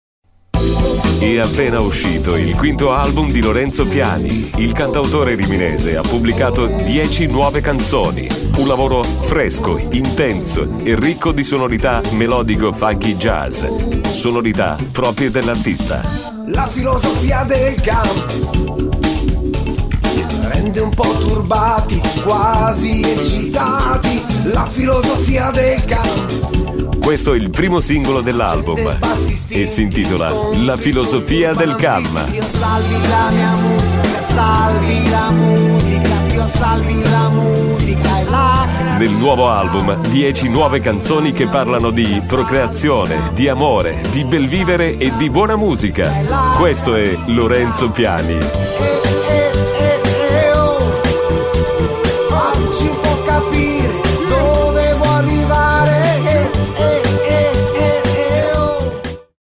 Voce classica radiofonica.